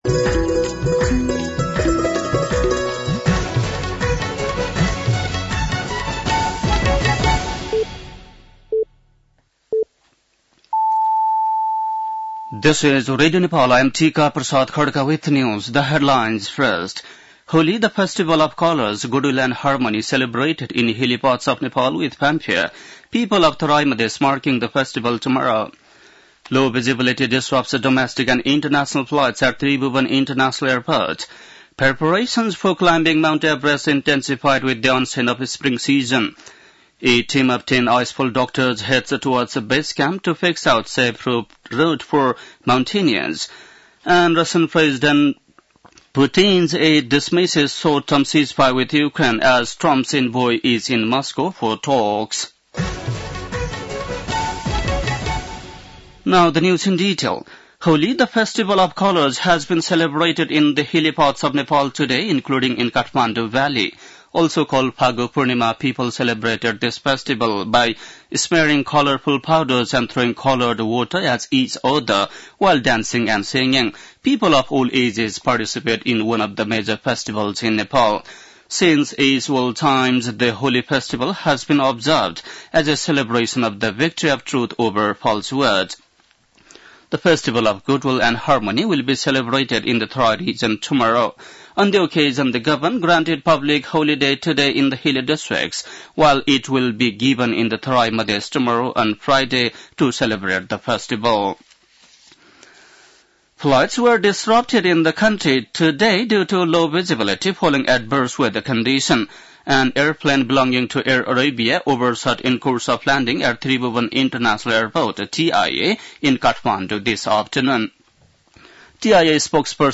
बेलुकी ८ बजेको अङ्ग्रेजी समाचार : ३० फागुन , २०८१